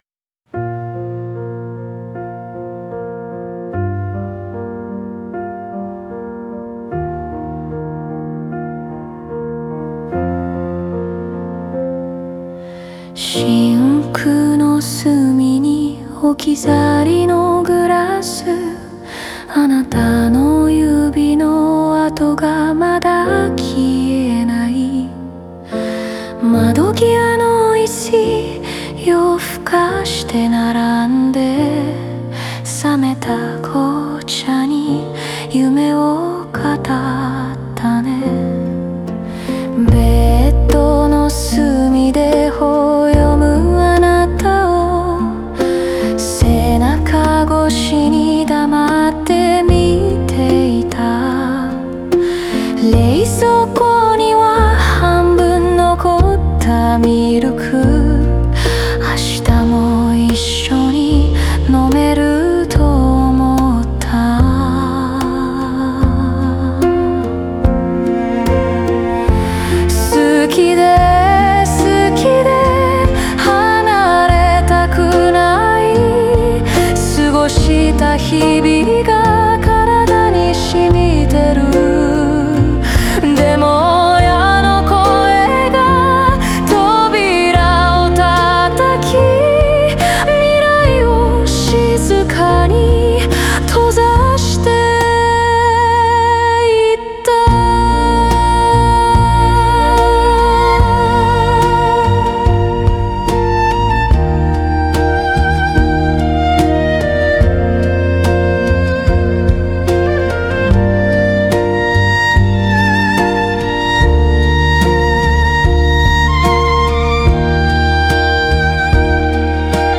声は甘く囁くように想定され、静かで黒い雰囲気の中に愛の切実さを滲ませています。